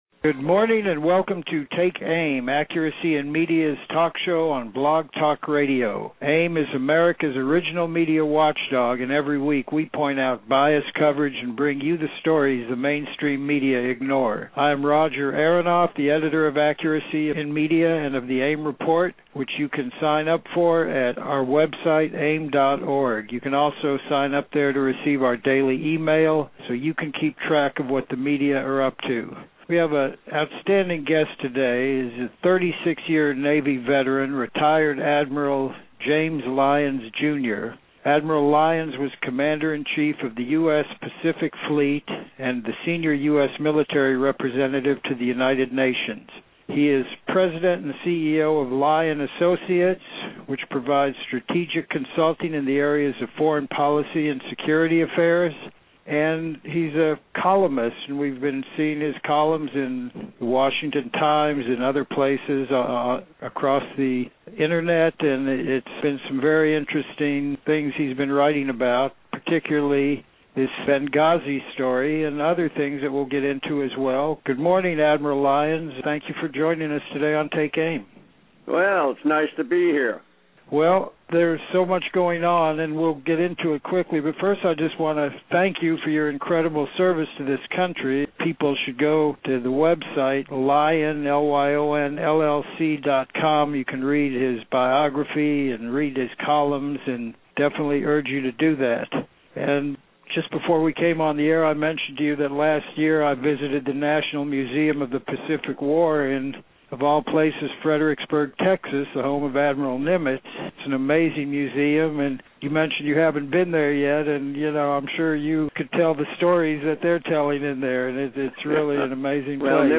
Interview with then-retired Admiral James “Ace” Lyons: